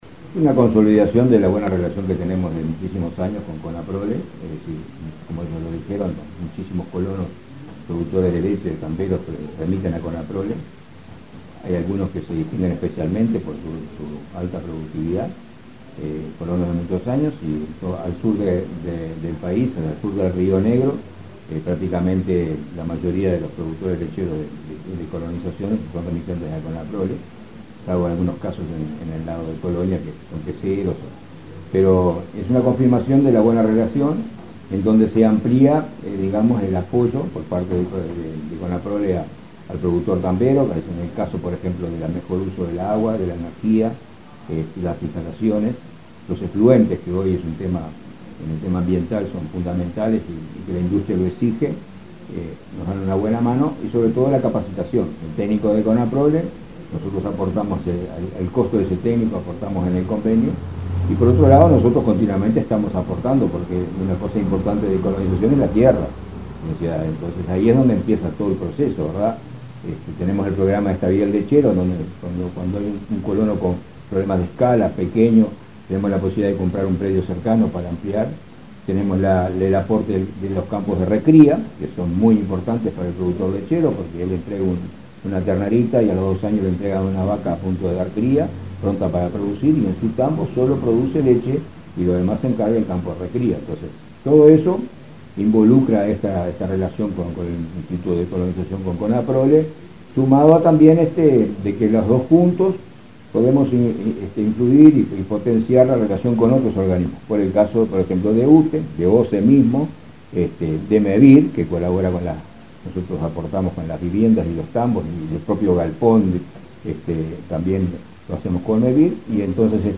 Declaraciones del presidente del INC, Julio Cardozo